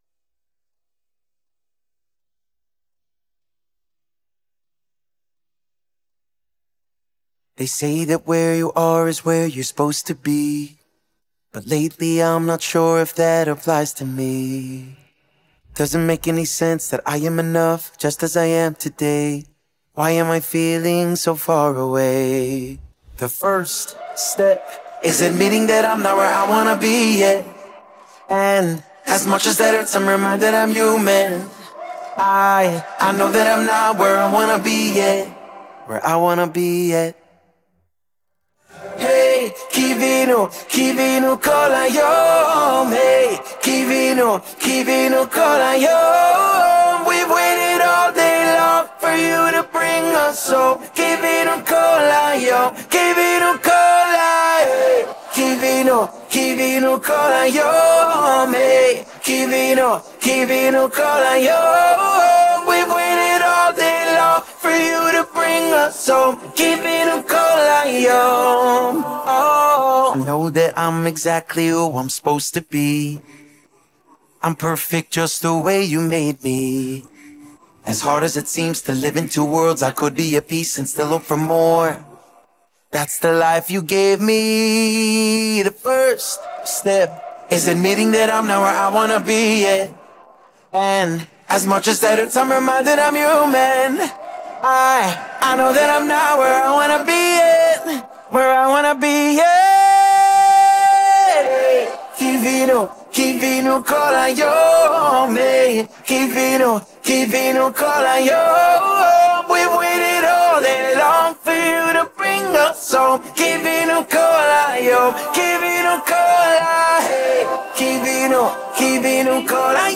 אני שומעת בו הד.
אני מעלה שיר ווקאלי.